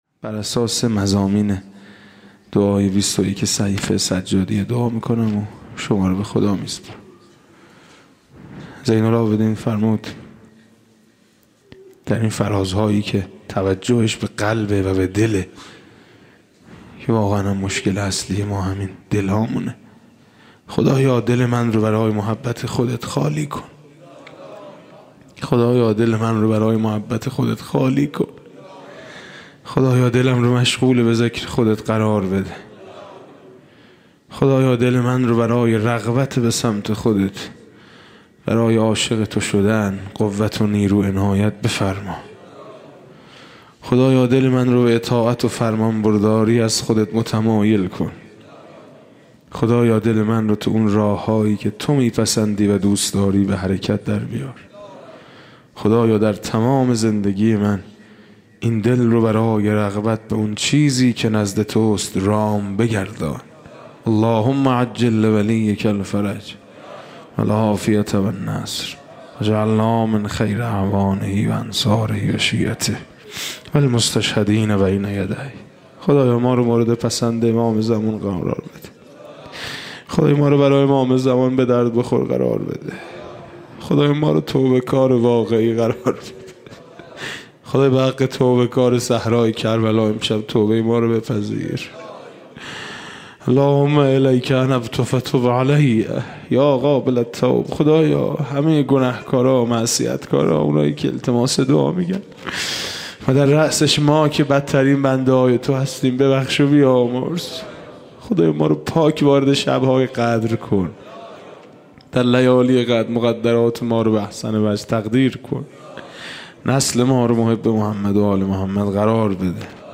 دانلود مناجات شب پنجم ماه رمضان الکریم با نوای حاج میثم مطیعی